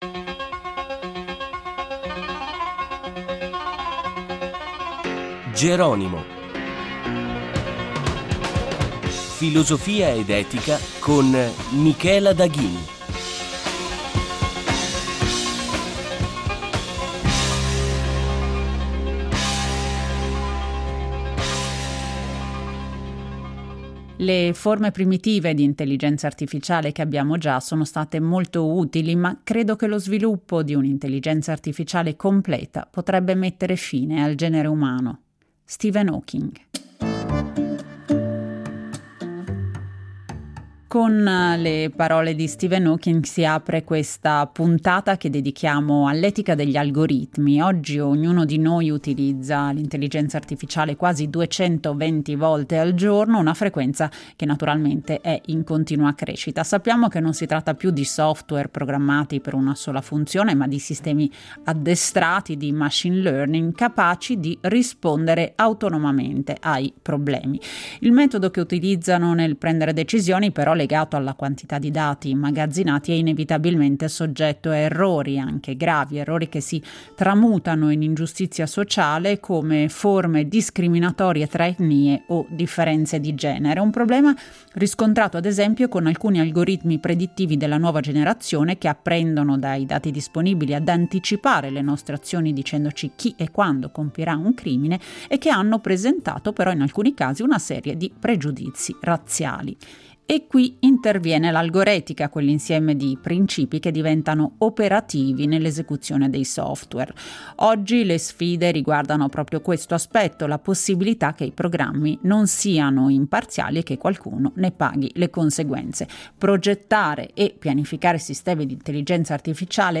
È possibile un’etica della computabilità, con l’implementazione di direttrici etiche o leggi nei sistemi di intelligenza artificiale? Ne abbiamo parlato con lo studioso, che da anni si occupa di etica, bioetica ed etica delle tecnologie.